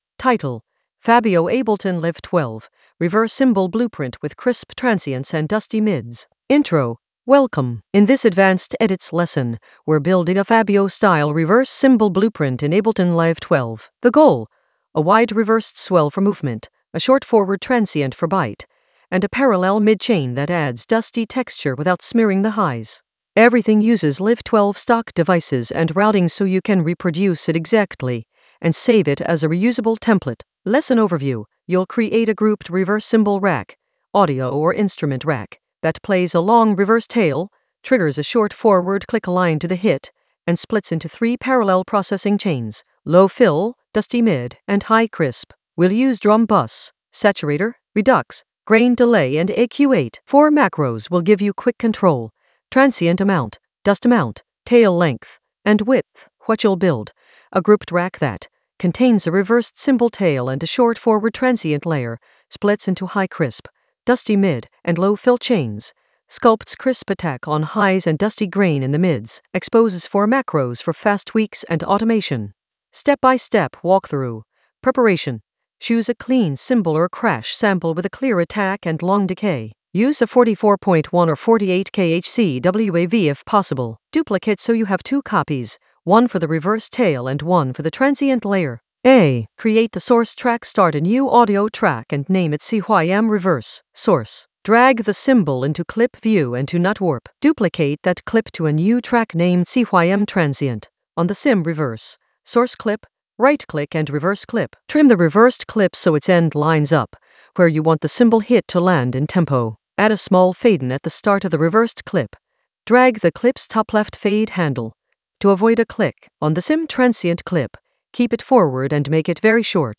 Narrated lesson audio
The voice track includes the tutorial plus extra teacher commentary.
An AI-generated advanced Ableton lesson focused on Fabio Ableton Live 12 reverse cymbal blueprint with crisp transients and dusty mids in the Edits area of drum and bass production.